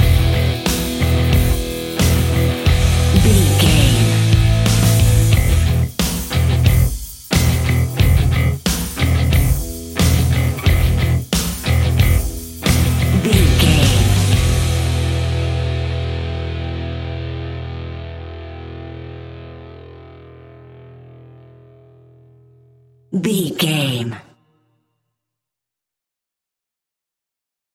Aeolian/Minor
Slow
hard rock
heavy metal
blues rock
distortion
rock guitars
Rock Bass
heavy drums
distorted guitars
hammond organ